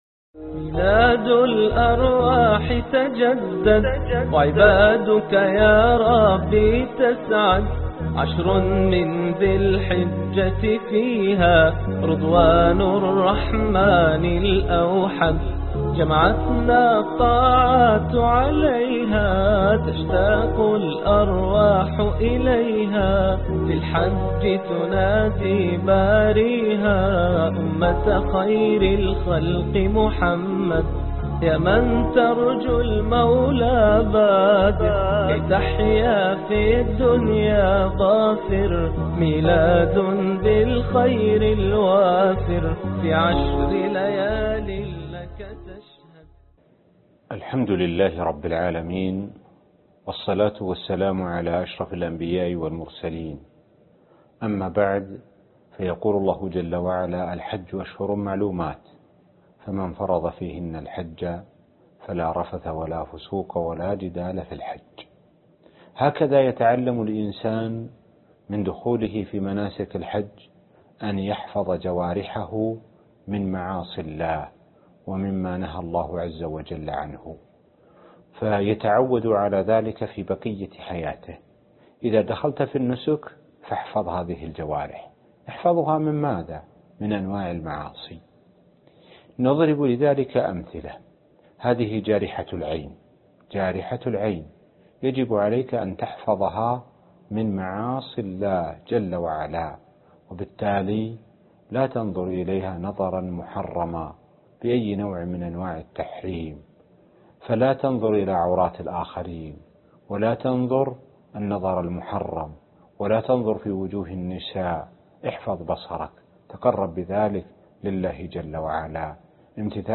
الدرس الثامن ( ميلاد جديد - الحج ) - الشيخ سعد بن ناصر الشثري